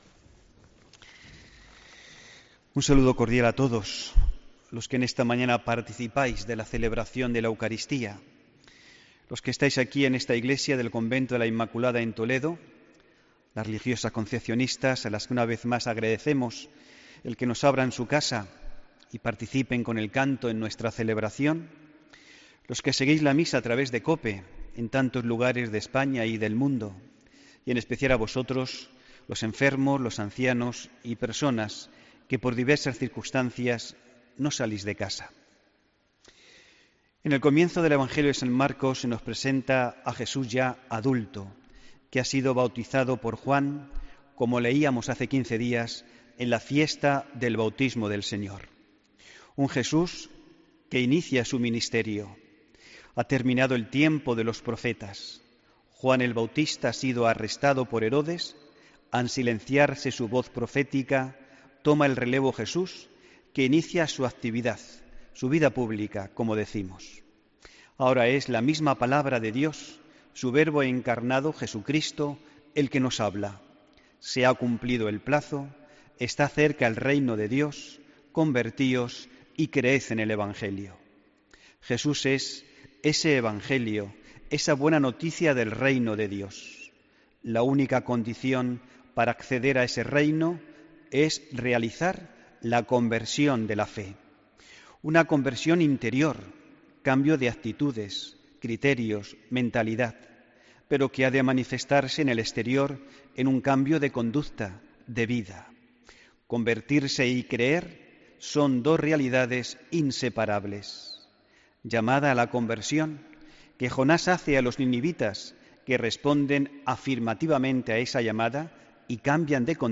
HOMILÍA 21 ENERO 2018